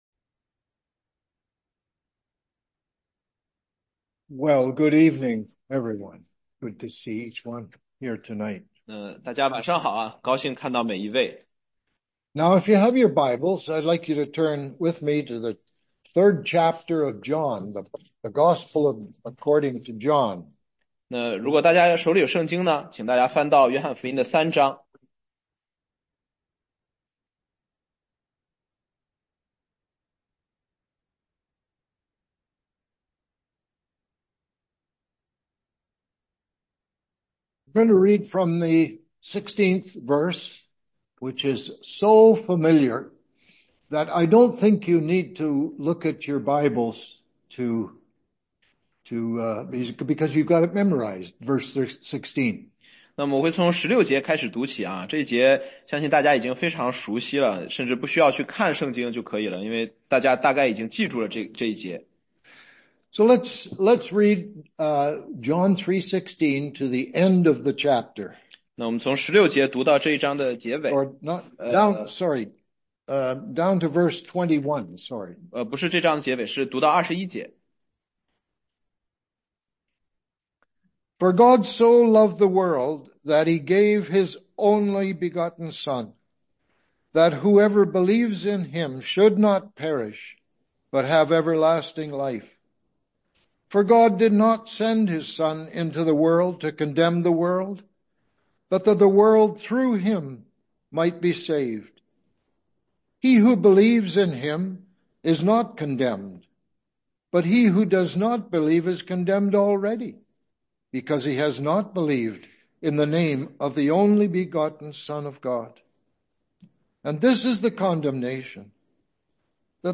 16街讲道录音 - 约翰福音解读—耶稣降世，真光照亮世界（3章16-21节）
中英文查经